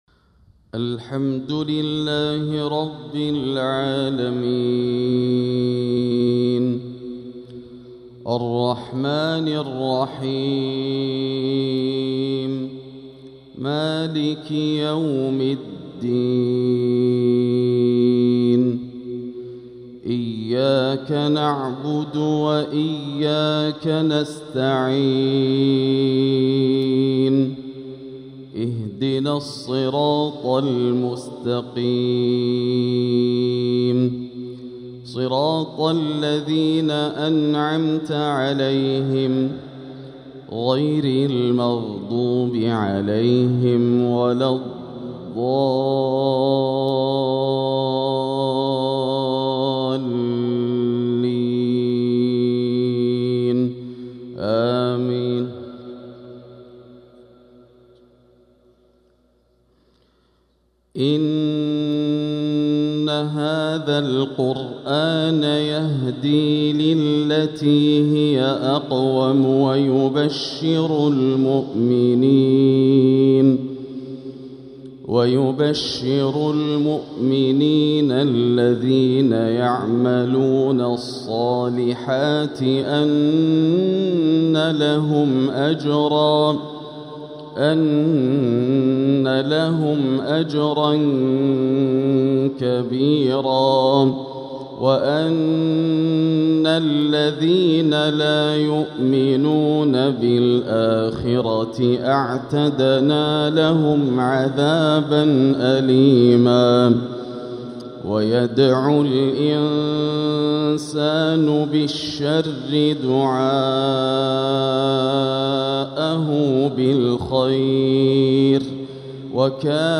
فجر الأحد 3-8-1446هـ | من سورة الإسراء 9-24 | Fajr prayer from Surat Al-Isra 2-2-2025 > 1446 🕋 > الفروض - تلاوات الحرمين